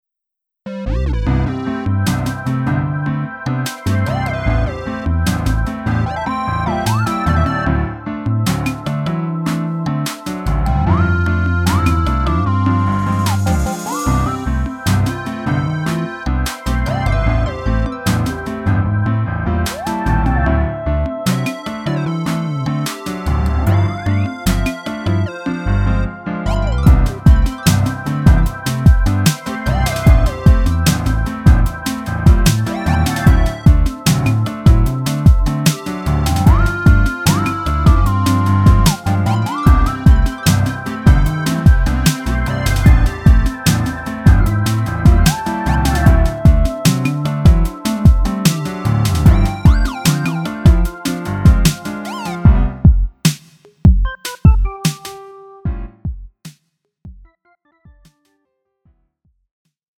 음정 원키 3:26
장르 가요 구분 Lite MR
Lite MR은 저렴한 가격에 간단한 연습이나 취미용으로 활용할 수 있는 가벼운 반주입니다.